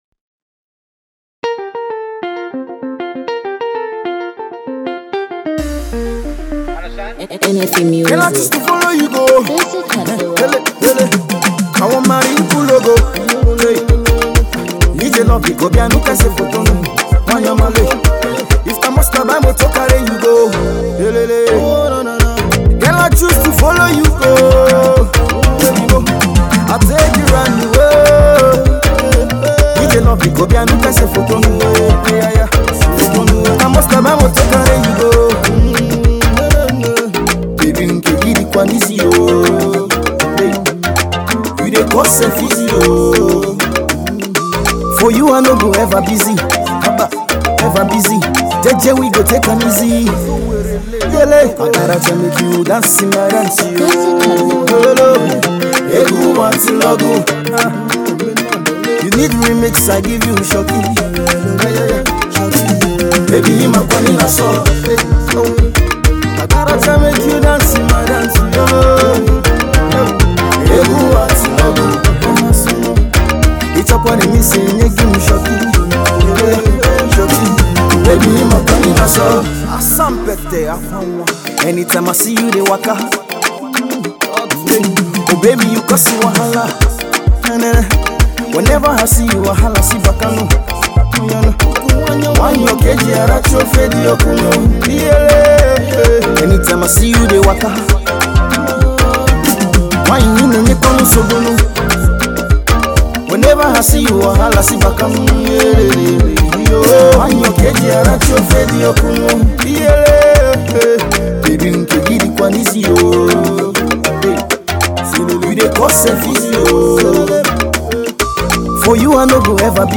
Indigenous Pop